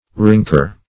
rinker - definition of rinker - synonyms, pronunciation, spelling from Free Dictionary Search Result for " rinker" : The Collaborative International Dictionary of English v.0.48: Rinker \Rink"er\, n. One who skates at a rink.